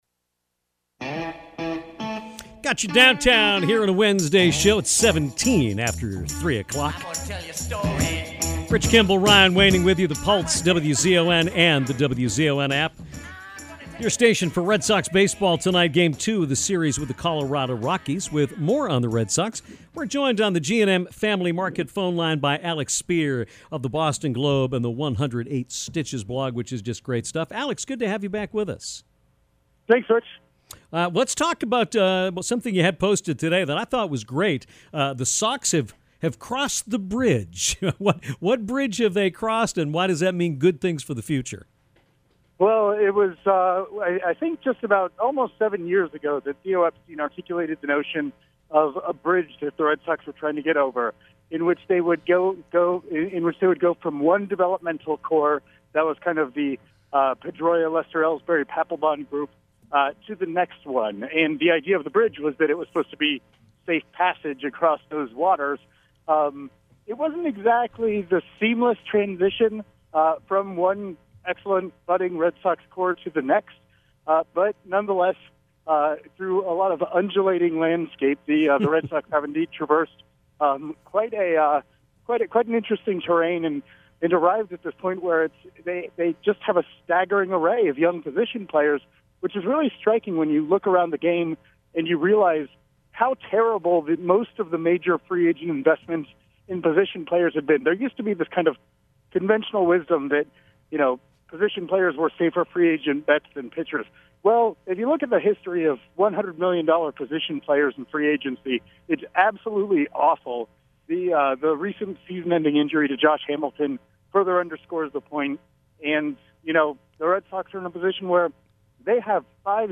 called us on his walk to the ballpark